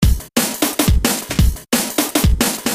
标签： 174 bpm Drum And Bass Loops Drum Loops 469.33 KB wav Key : Unknown